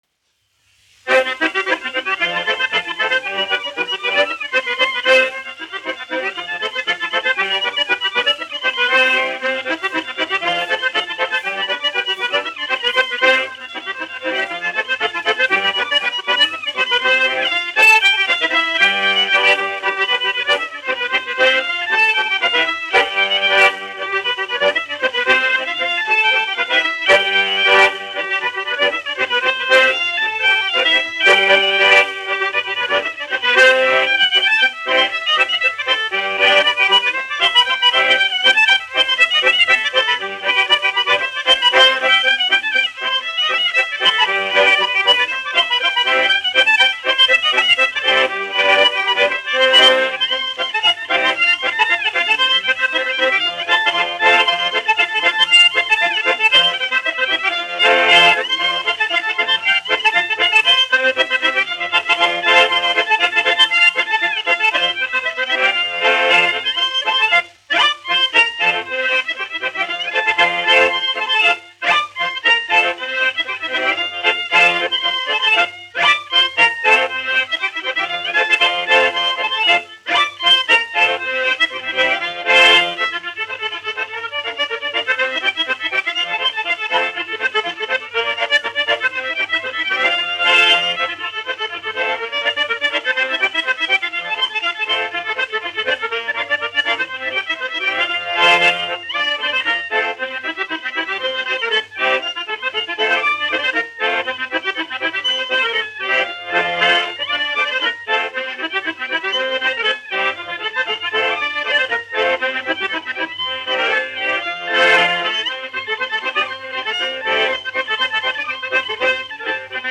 1 skpl. : analogs, 78 apgr/min, mono ; 25 cm
Polkas
Populārā instrumentālā mūzika
Ermoņiku solo
Skaņuplate